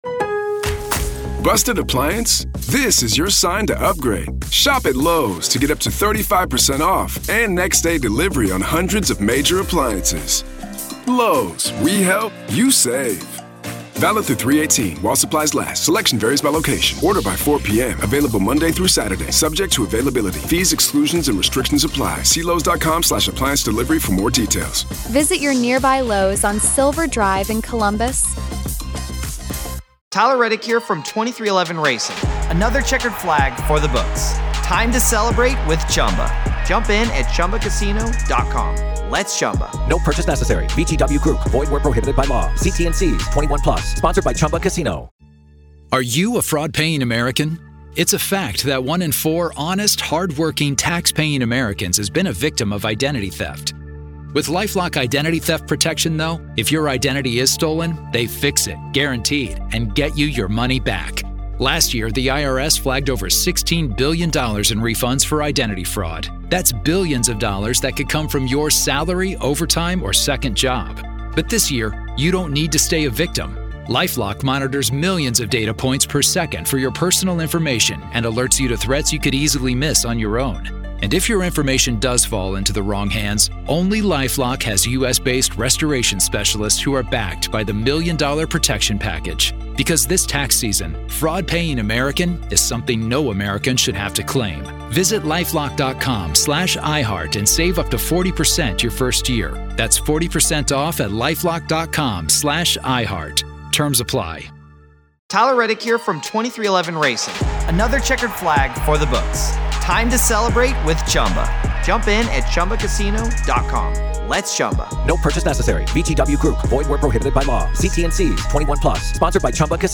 True Crime News & Commentary